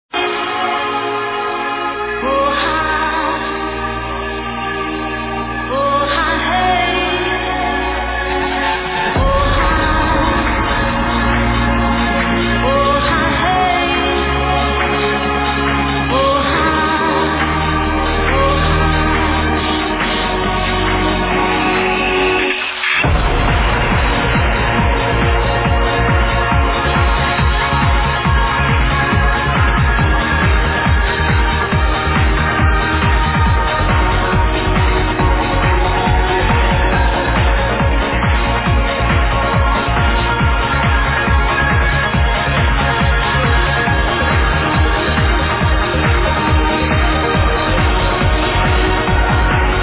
played this remix live